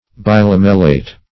Search Result for " bilamellate" : The Collaborative International Dictionary of English v.0.48: Bilamellate \Bi*lam"el*late\, Bilamellated \Bi*lam"el*la`ted\, a. [Pref. bi- + lamellate.]